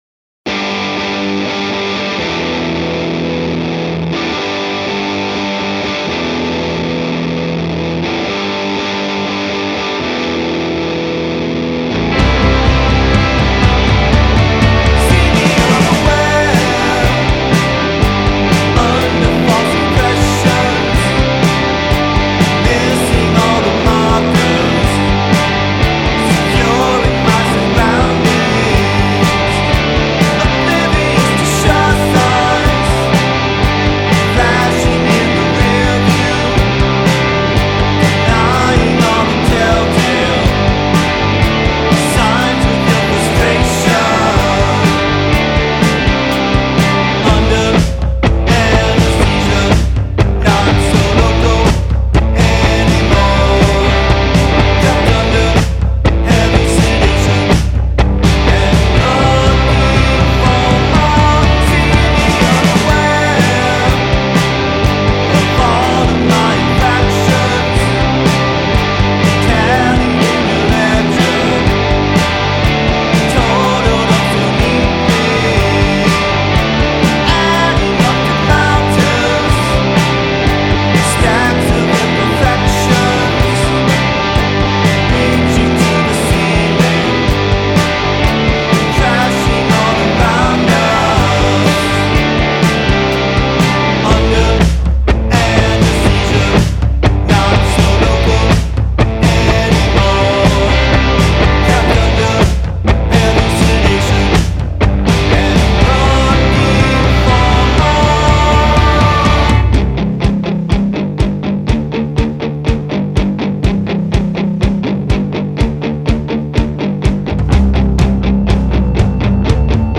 bright rock ‘n’ roll
full, satisfying guitar work